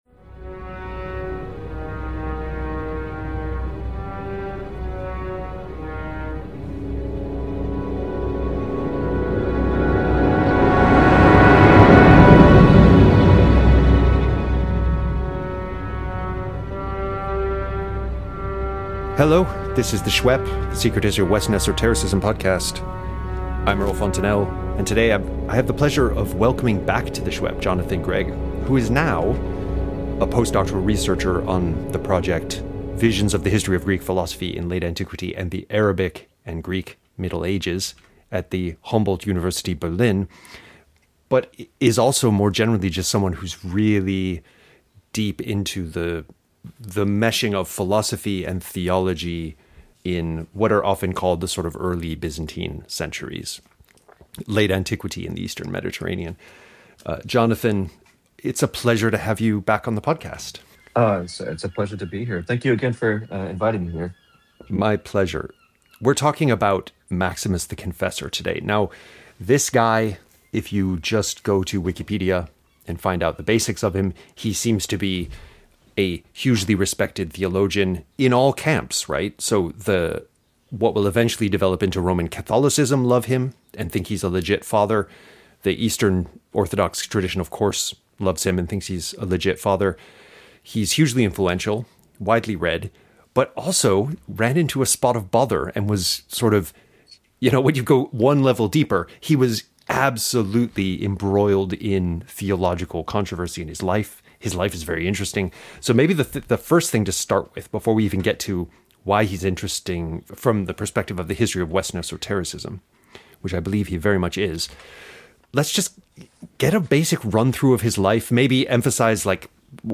There are various discursus in the conversation, as we delve into the Christological disputes which got Maximus into so much trouble, over the question of Christ’s faculty of will and whether he had a single will, human and divine (‘monothelitism’), or whether each of his two natures had its own will, as well as musing on the liminal politico-religious role played by monks and ascetics in Orthodox Christianity and the East Roman state. Finally, we discuss Maximus’ apophatic approach to god’s (non)nature; unlike Gregory of Nyssa, for whom god was an unlimited sea of being, god is, for Maximus, beyond being altogether.